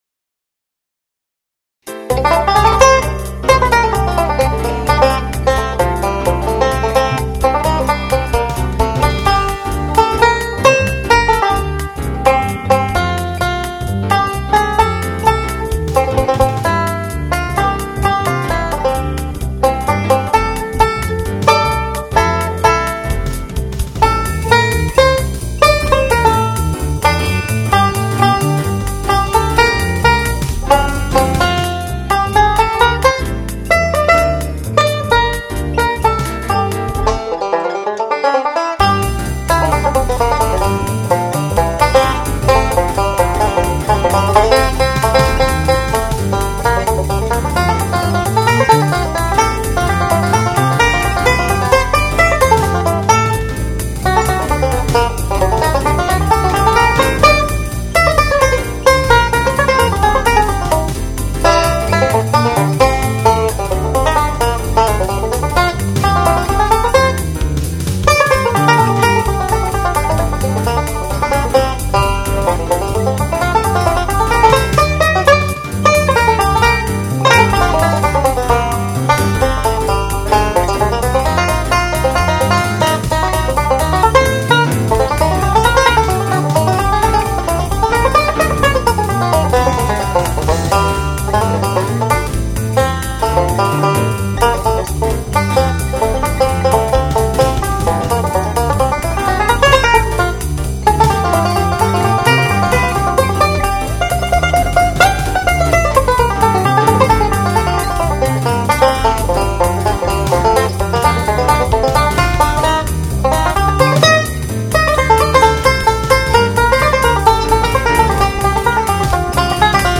Bebop sax lines on a banjo.